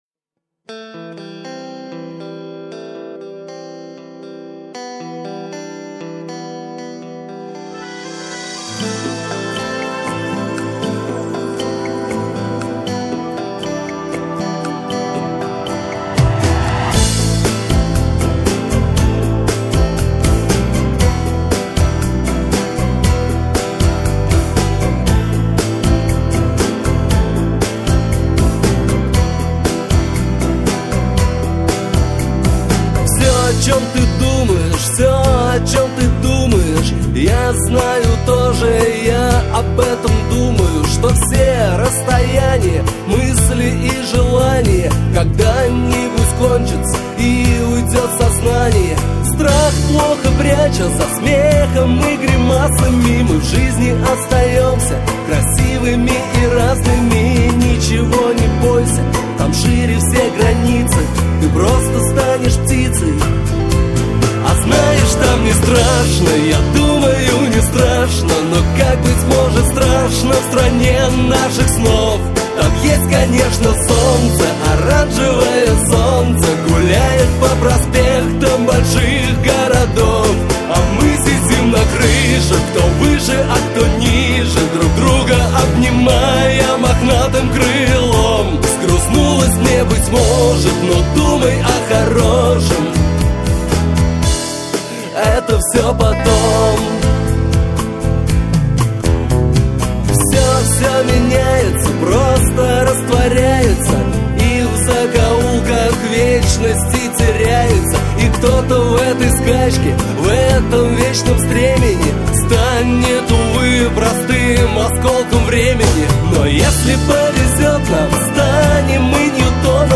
Категорія: Pop